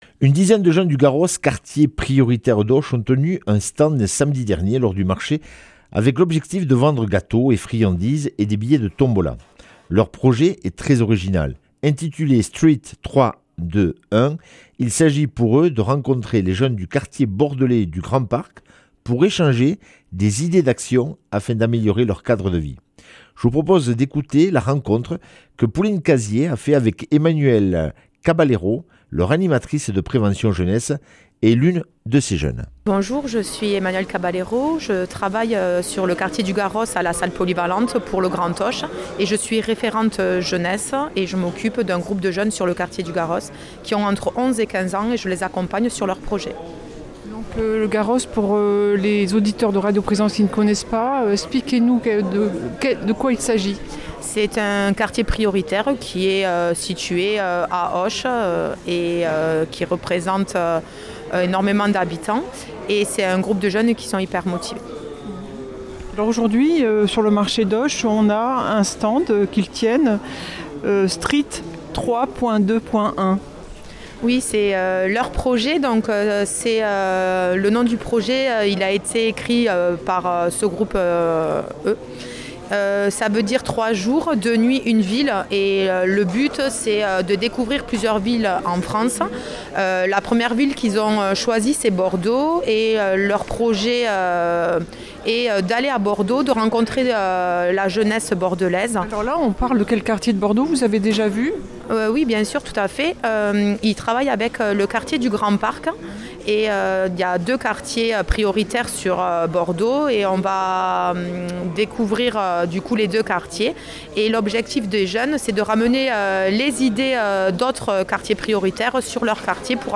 Interview et reportage du 04 févr.